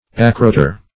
Search Result for " acroter" : The Collaborative International Dictionary of English v.0.48: Acroter \Ac"ro*ter\ ([a^]k`r[-o]*t[~e]r or [.a]*kr[=o]*t[~e]r), n. [F. acrot[`e]re.